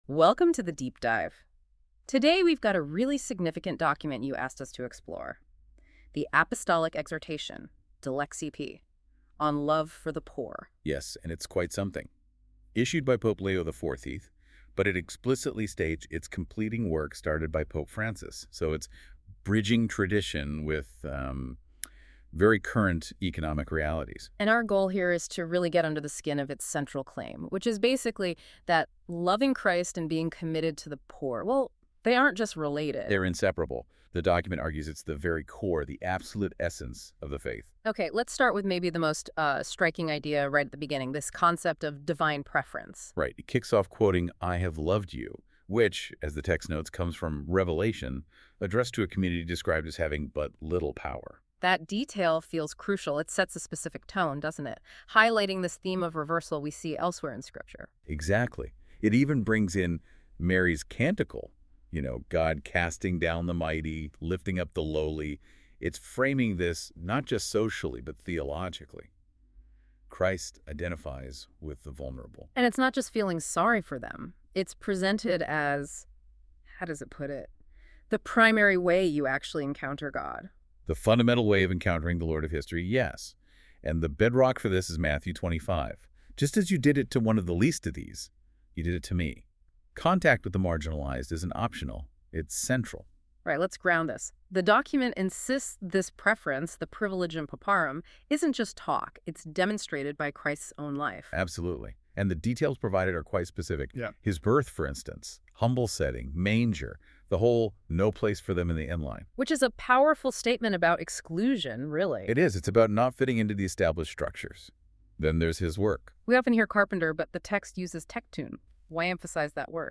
Listen to this AI-powered Dilexi Te summary.